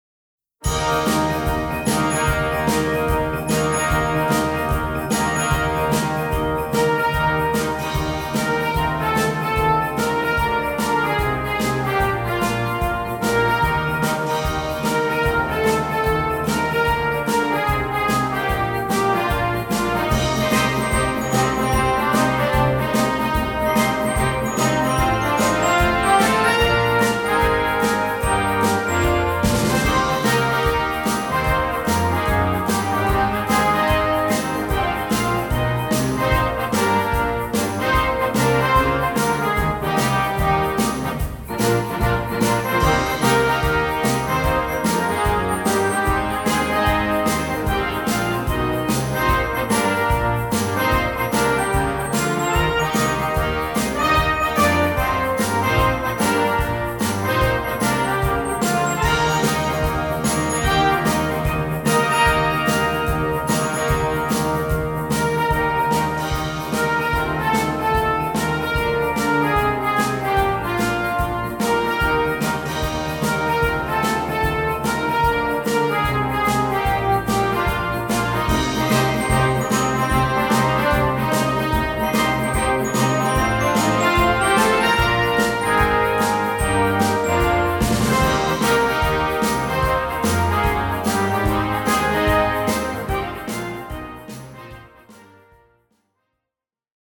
Gattung: Poptitel
Besetzung: Blasorchester